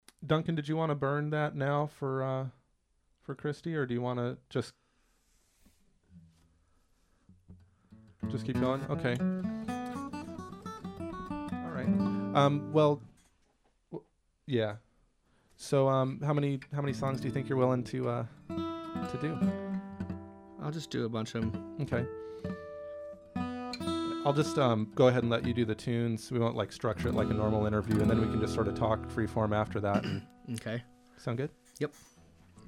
Here's a great interview with Curt from Tucson's KXCI community radio station, along with some tasty tunes to flesh it out.
07-Interview3.mp3